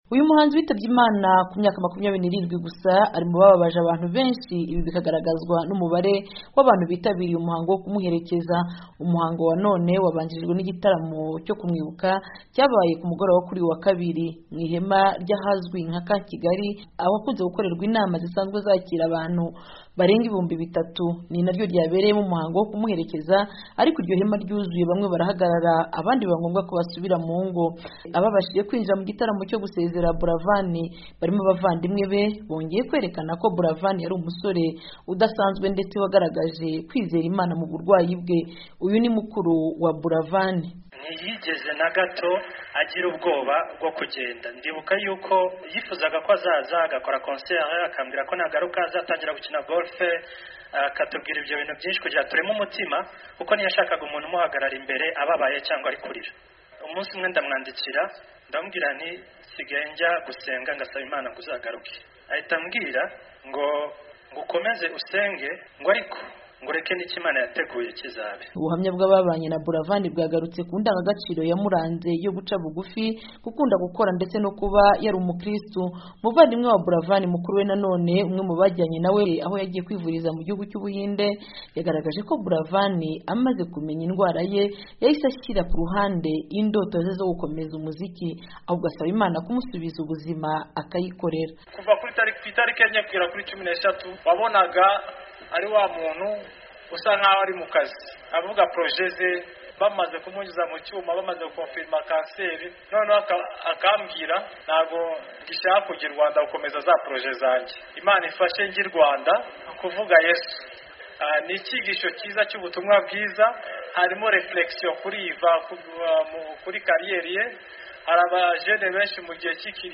Umunyamakuru w'Ijwi ry'Amerika wakurikiye uwo muhango yaduteguriye inkuru irambuye mushobora kumva hano hepfo.